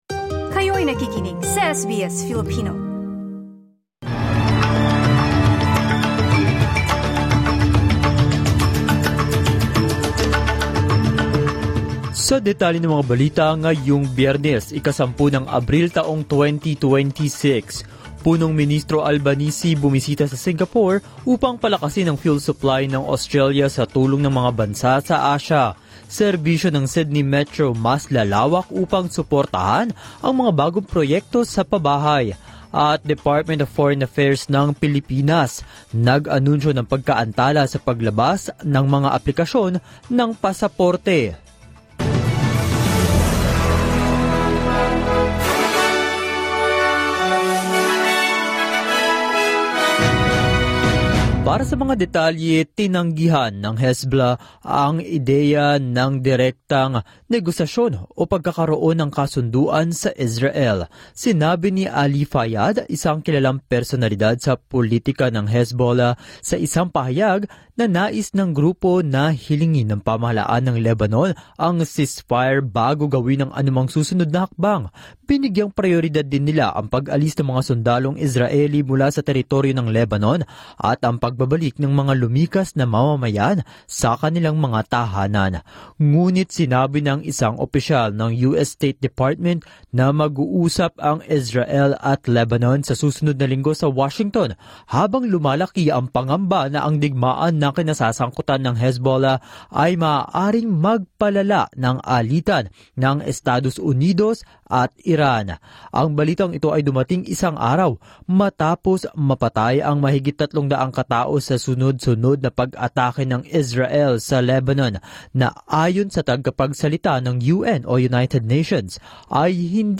SBS News in Filipino, Friday, 10 April 2026